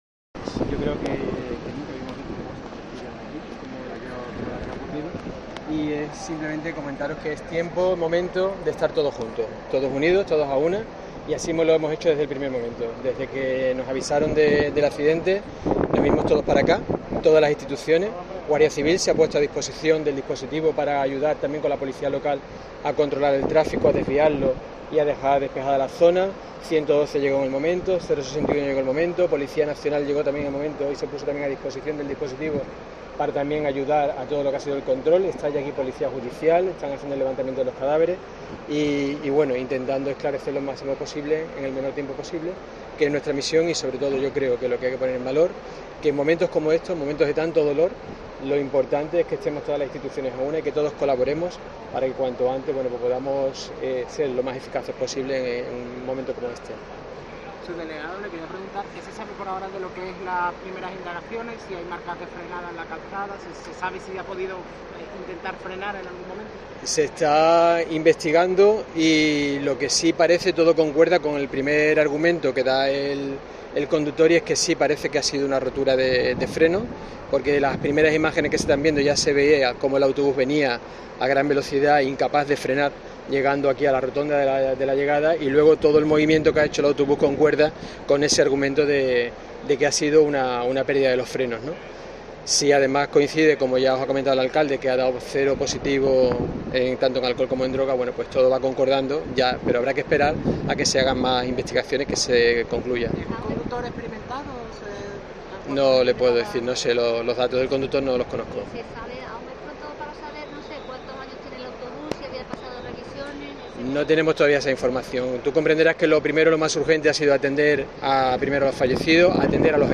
El subdelegado del Gobierno en Cádiz, José Pacheco habla de fallo en los frenos